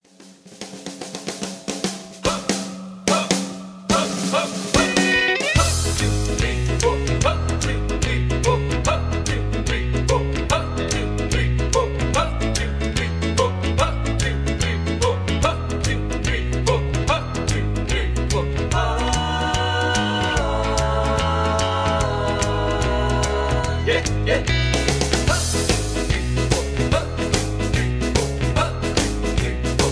karaoke, backing tracks
rock